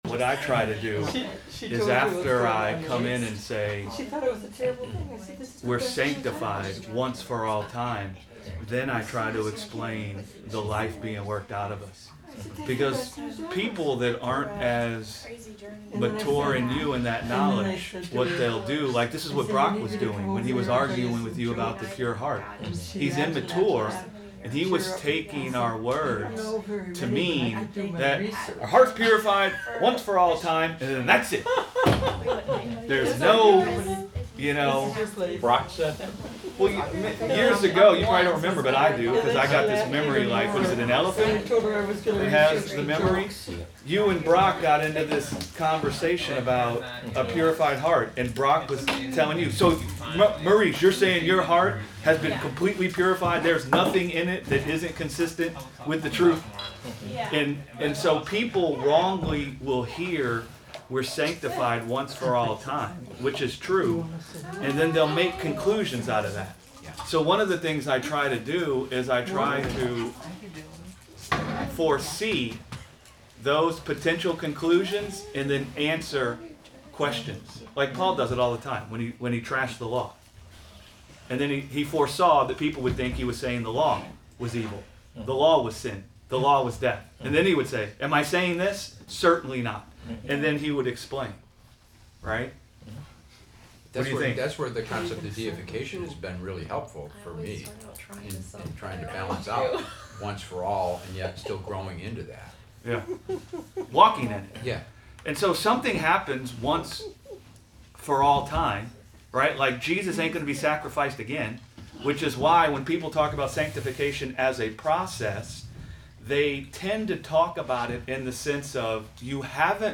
Sunday Bible Study: Sanctification & the Nature of Man - Gospel Revolution Church